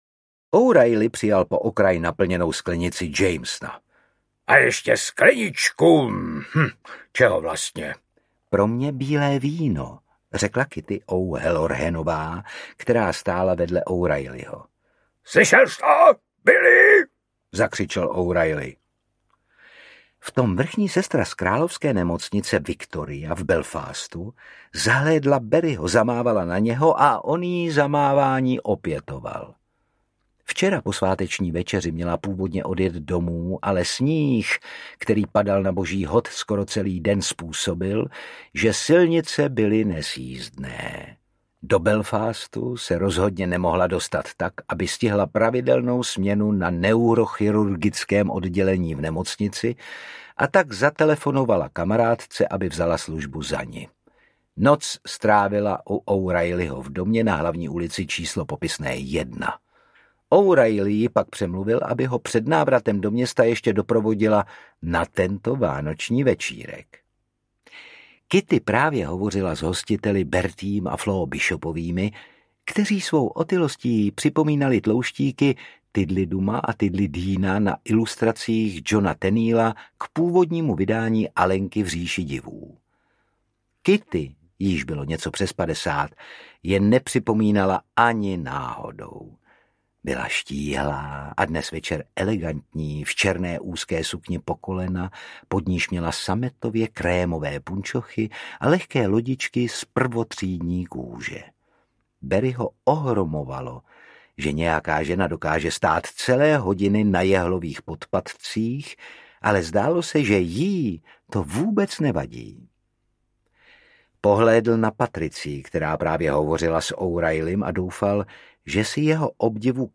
Doktore, bacha na ženský! audiokniha
Ukázka z knihy
Čte Otakar Brousek.
• InterpretOtakar Brousek